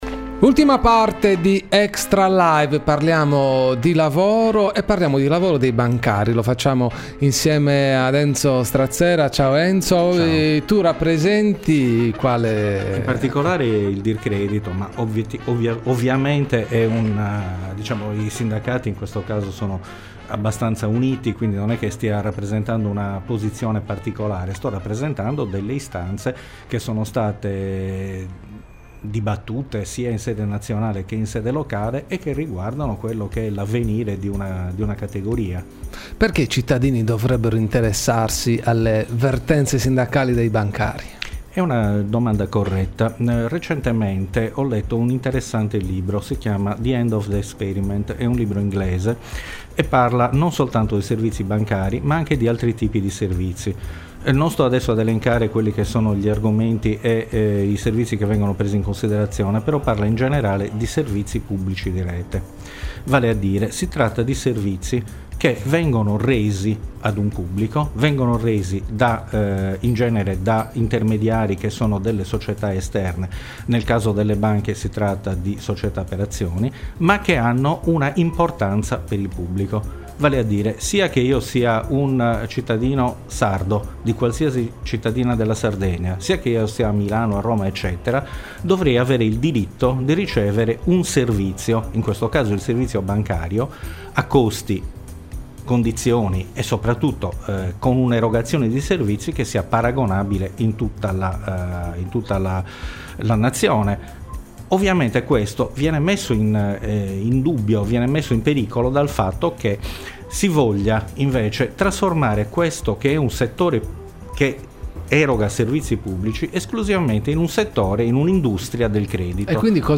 Ai microfoni di Radio X